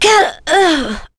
Chrisha-Vox_Damage_03.wav